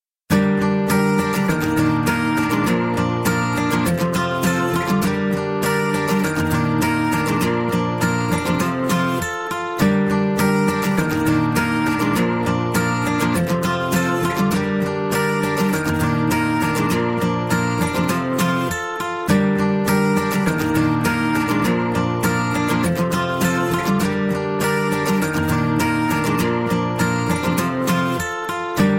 Gitarr, Android, Ljudeffekter, Instrumentalmusik